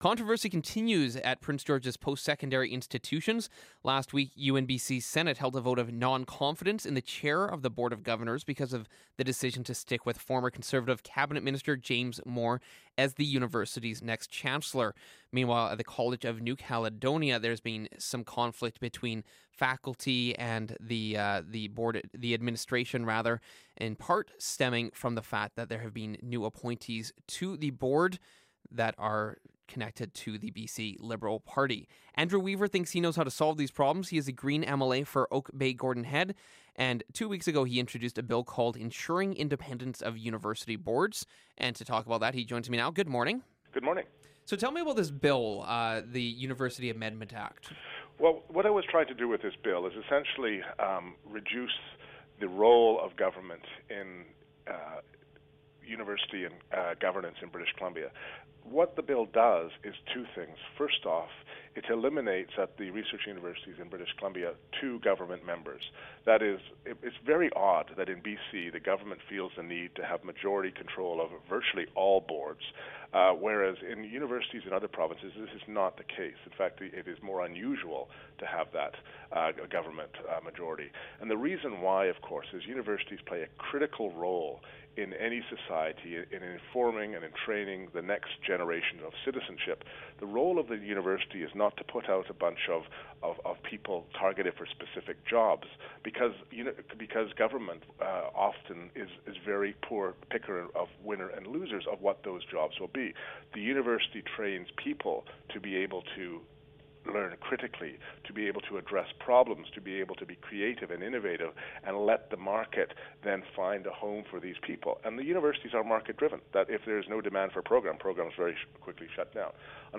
In response to ongoing controversies at UBC and UNBC, Green MLA Andrew Weaver has introduced the University Amendment Act, 2016, to make university boards more independent. Listen to him speak about the proposal.